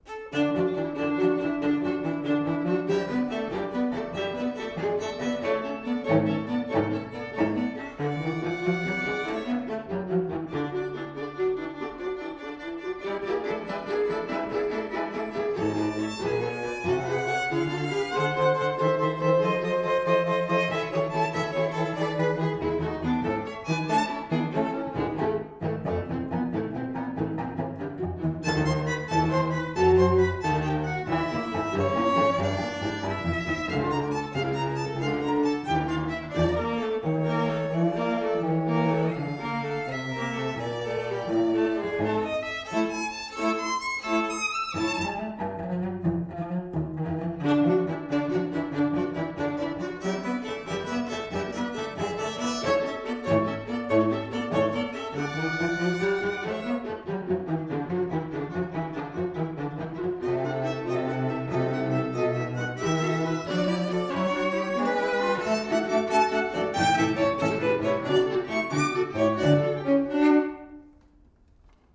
Third is a bouncing Menuetto presto full of forward energy.